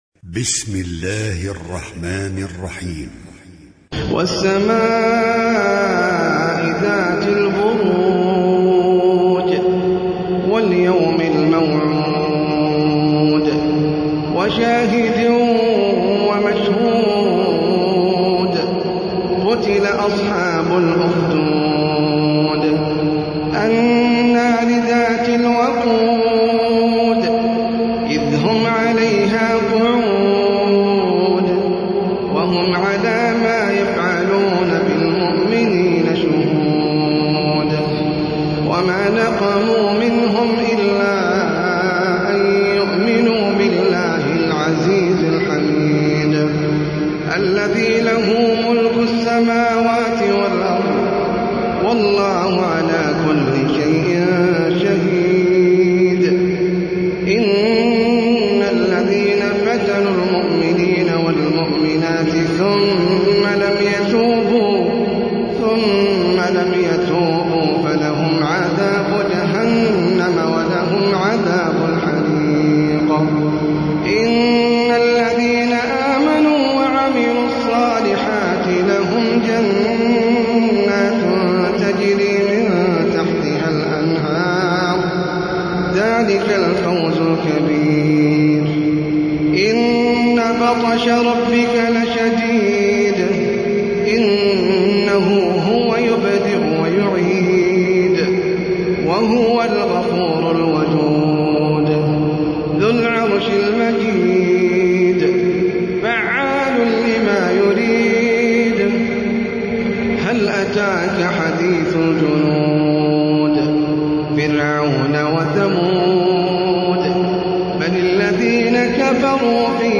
سورة البروج - المصحف المرتل (برواية حفص عن عاصم)
جودة عالية